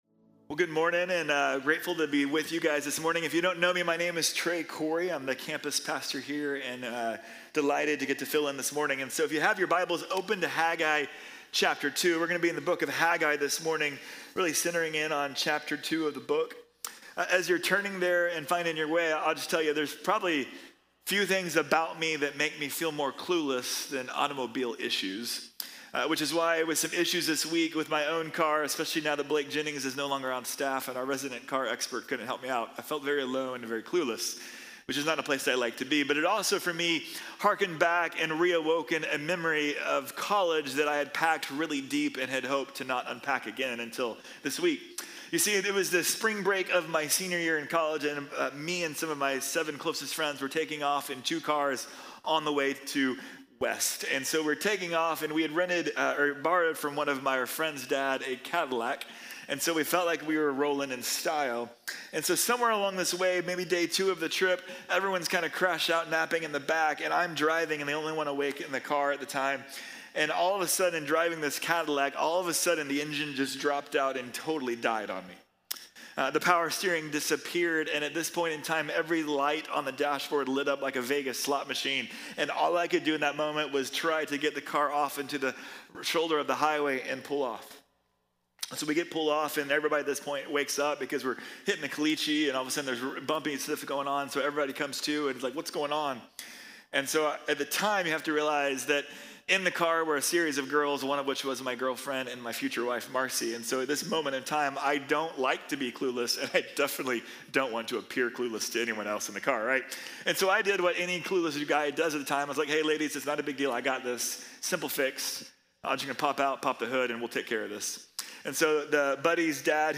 Haggai | Sermon | Grace Bible Church